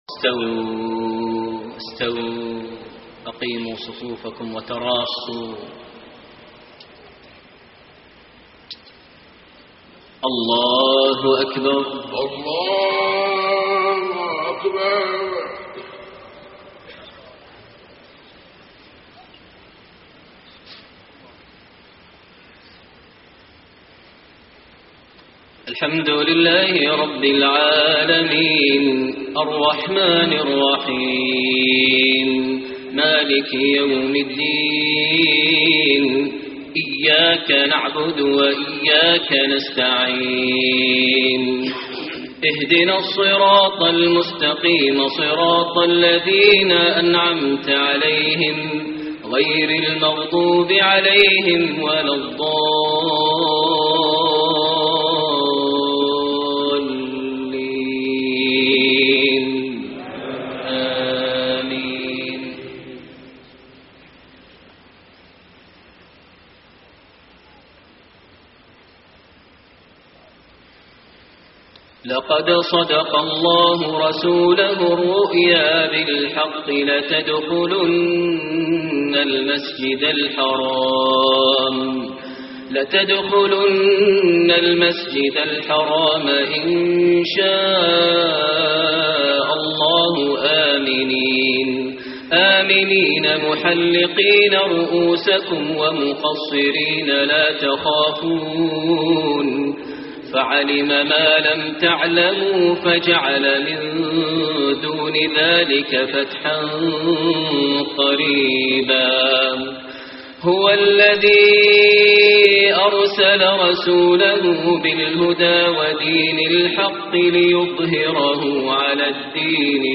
صلاة المغرب 2-7-1434 هـ خواتيم سورة الفتح > 1434 🕋 > الفروض - تلاوات الحرمين